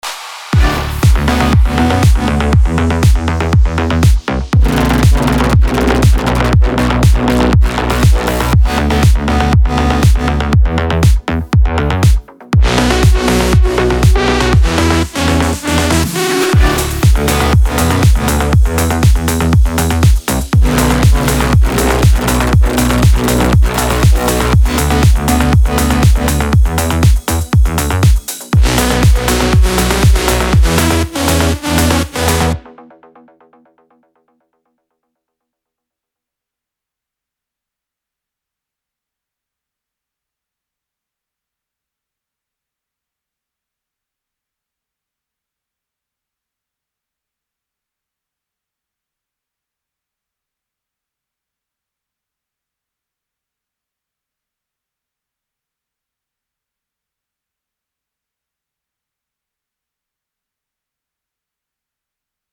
זה קצב משוגע לגמרי